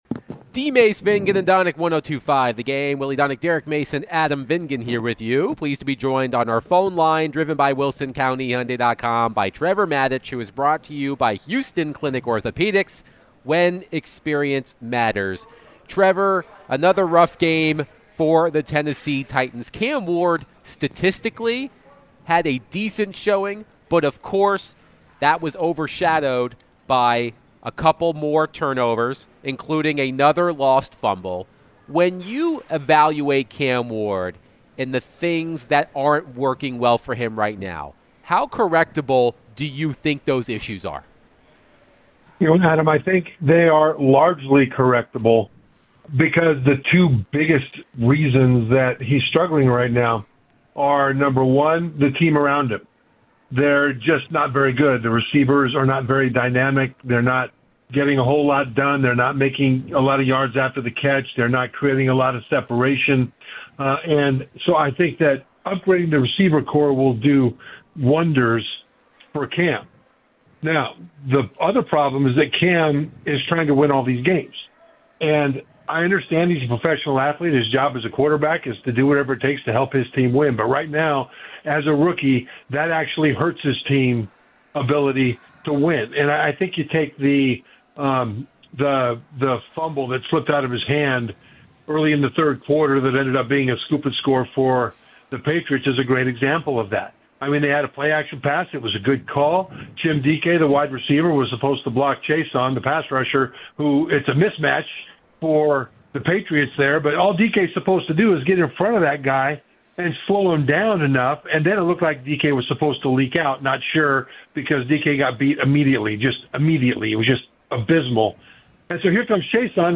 ESPN NFL Analyst Trevor Matich joined DVD to discuss the Titans 31-13 loss to the Patriots, Cam Ward, CFB, and more.